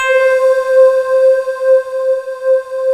55a-syn01-C3.wav